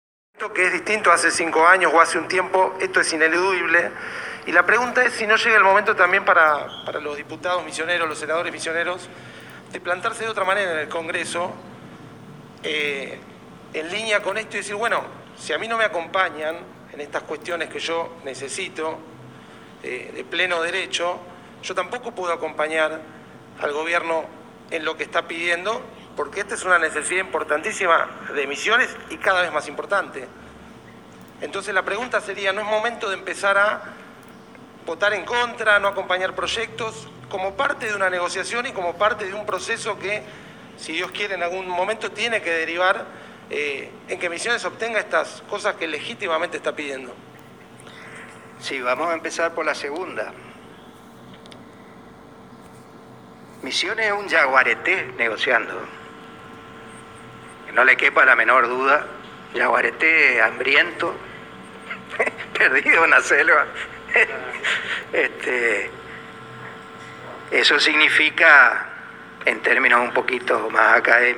“Los diputados renovadores en el Congreso Nacional son yaguaretés, yaguaretés hambrientos”, dijo Carlos Rovira el jueves 14 de octubre pasado, en una conferencia de prensa en la Sala de las Dos Constituciones de la Legislatura.
AUDIO, Conferencia de prensa C. Rovira Legislatura 14-10-21, Sobre pedido de compensación histórica por preservación de la Selva Misionera.